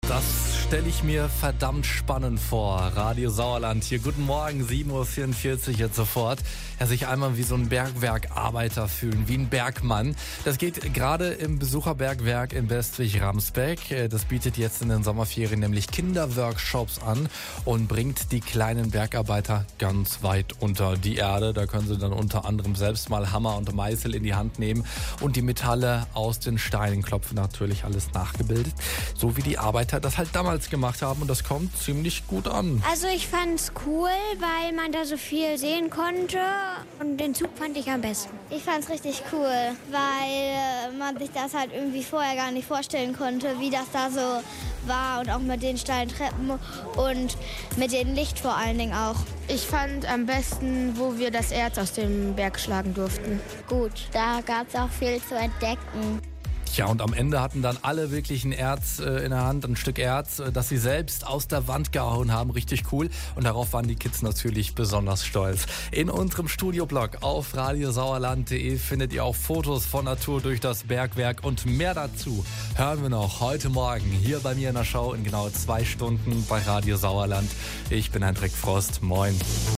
Das Besucherbergwerk in Bestwig-Ramsbeck bietet in den Sommerferien Kinderworkshops an. Wir haben eine Kindergruppe "unter Tage" begleitet.